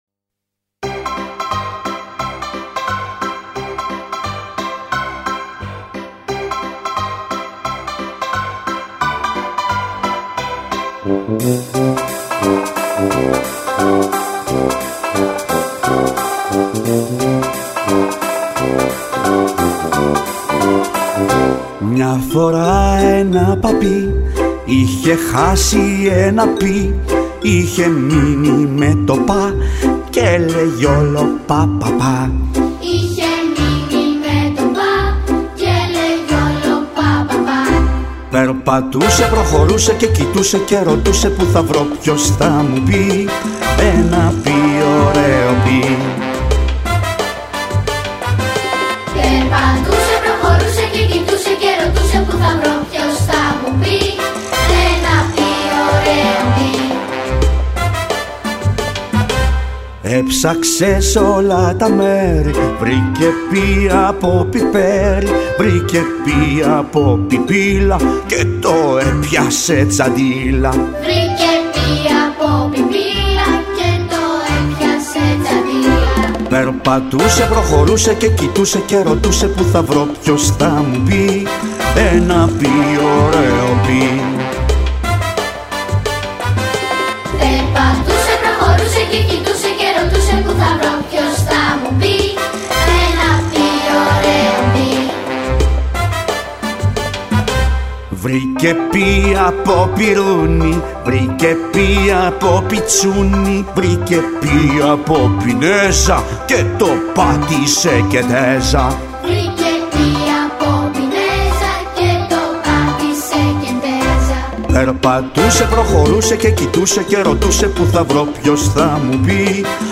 μελοποιημένα αποσπάσματα
αλλά και παιδιά δημοτικών σχολείων.